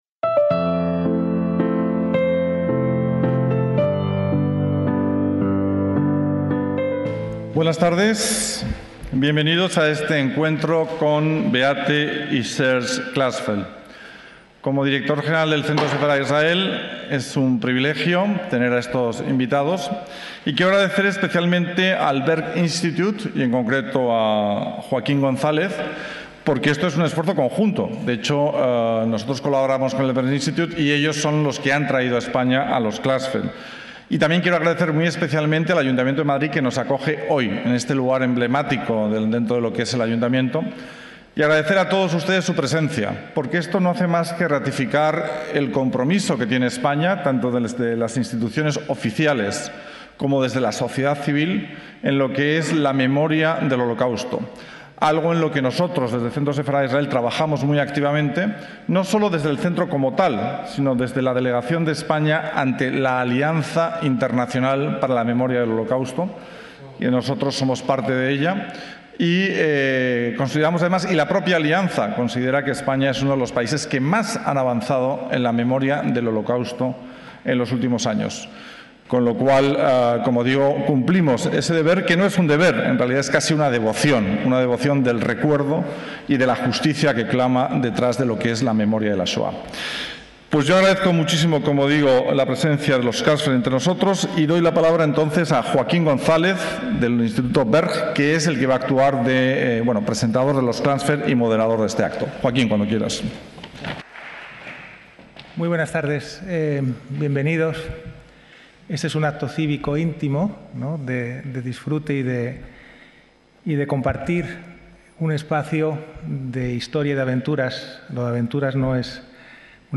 ACTOS EN DIRECTO - El 7 de julio de 2021 tuvo lugar en la Caja de Música del Palacio de Cibeles de Madrid un encuentro organizado por el Centro Sefarad Israel y el Berg Institute de Derechos Humanos con Beate y Serge Klarsfeld.